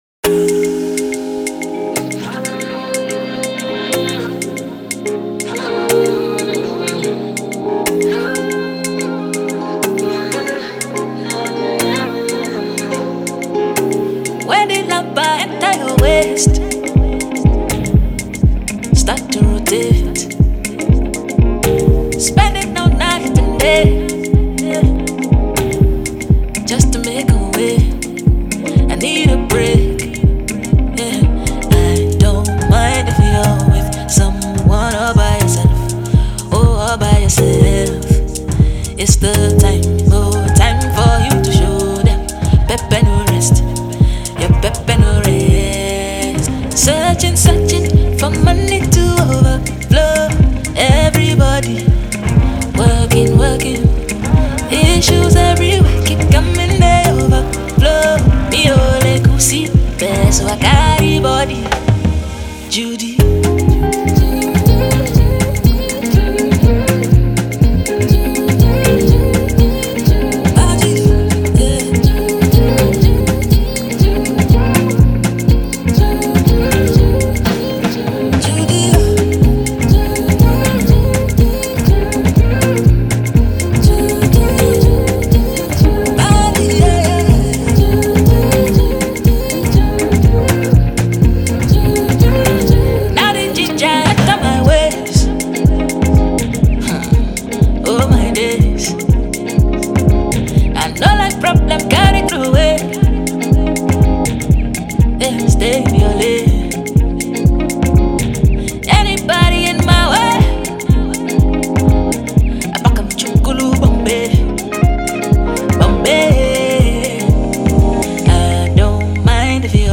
Afro-Jazz